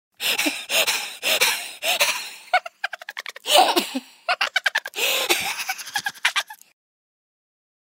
Звуки злодея
Смех после натворенных дел